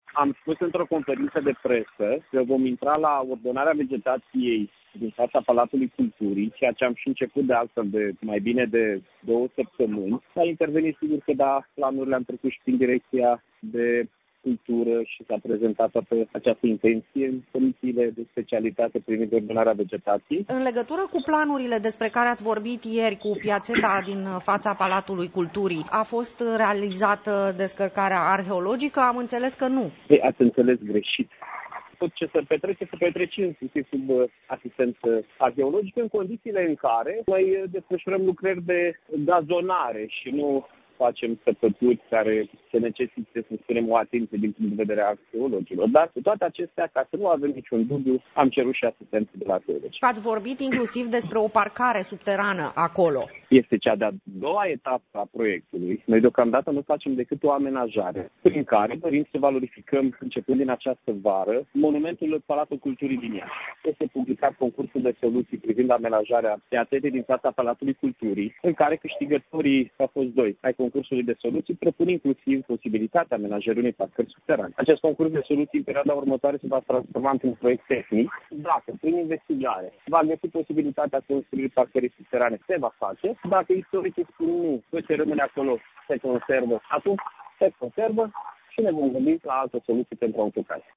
Primarul Mihai Chirica a declarat, pentru postul nostru de radio, că în ceea ce priveşte proiectul de reamenajare a piaţetei din faţa Platului Culturii, acesta a fost prezentat, anterior, Comisiei de Cultură, dar şi comisiilor care se ocupă de ordonarea vegetaţiei.